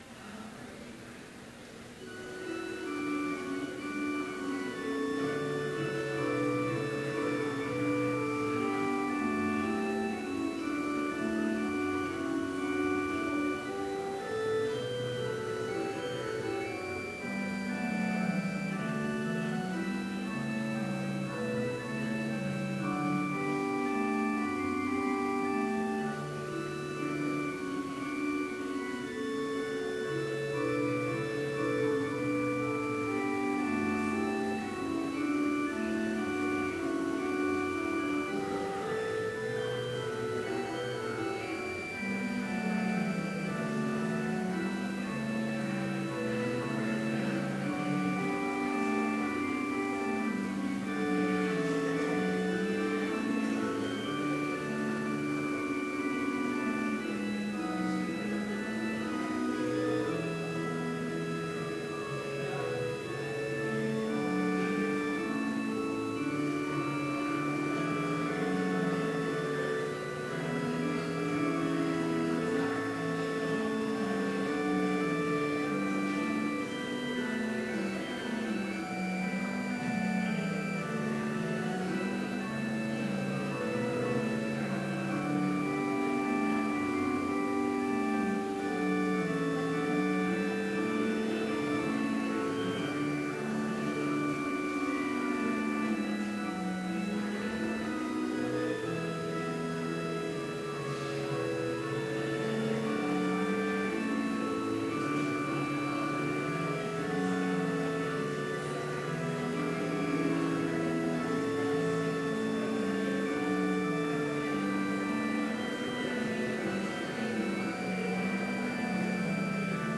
Complete service audio for Chapel - May 4, 2016